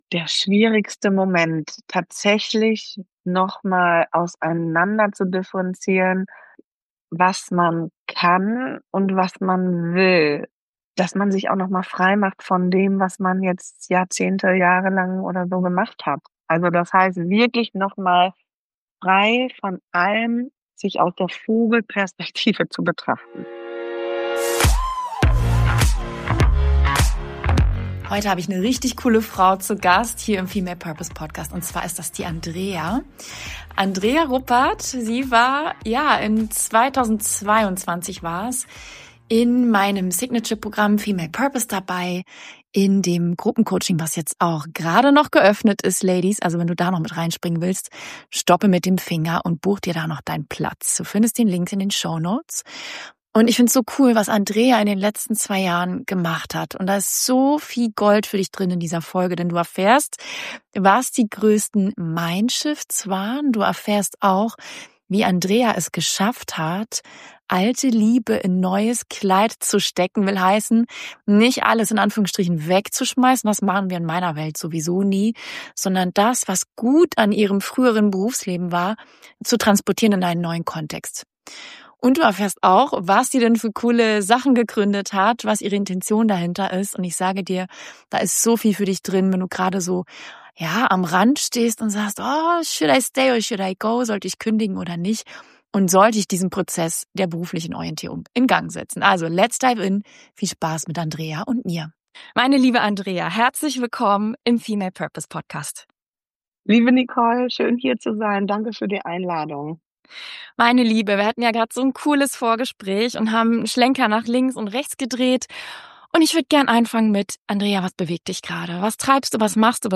Client Interview